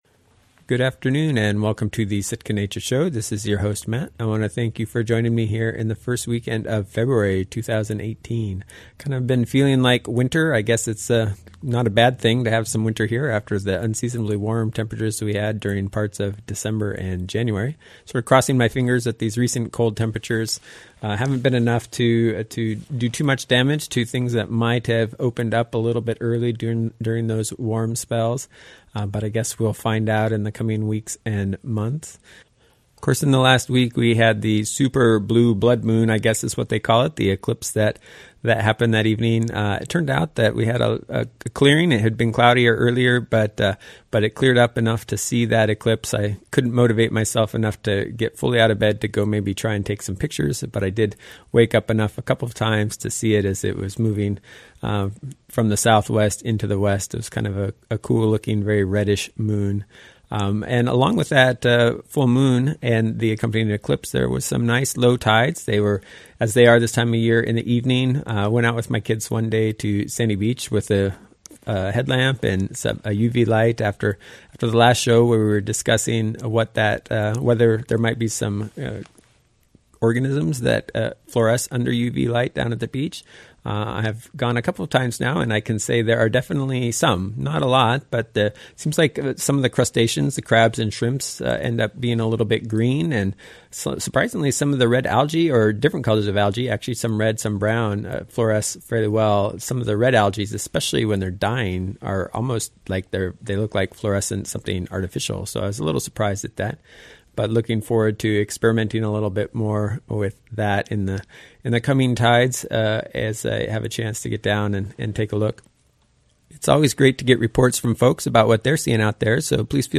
Sitka Nature Show Interview